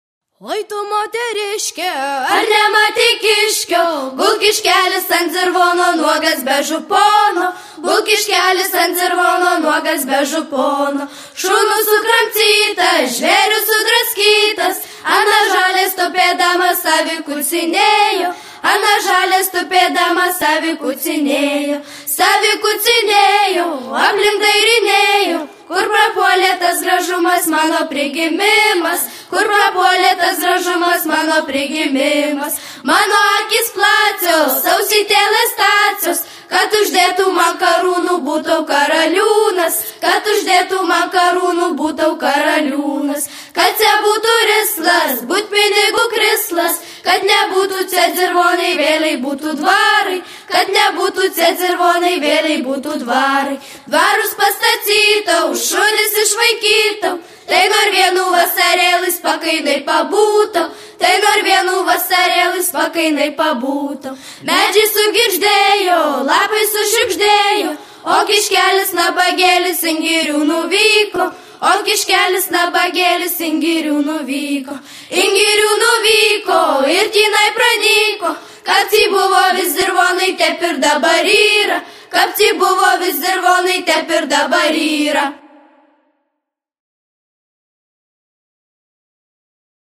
vaikų grupė